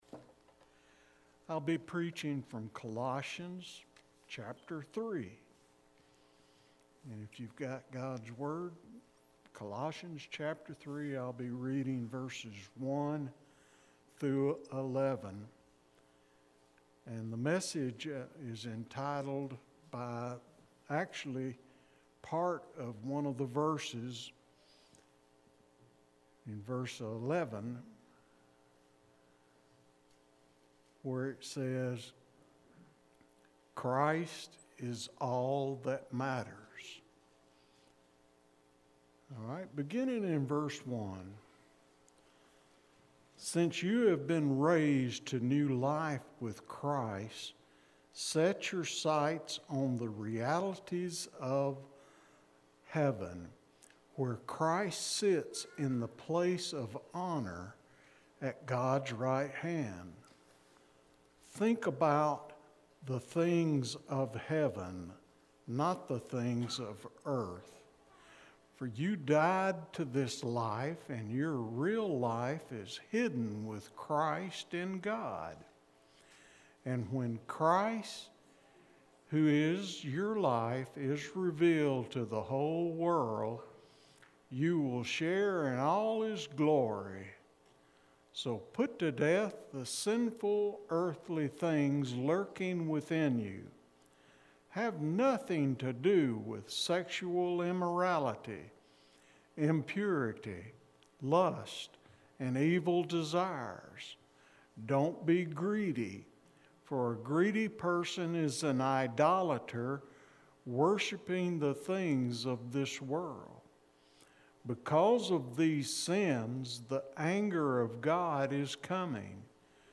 Sept-6-sermon-Audio.mp3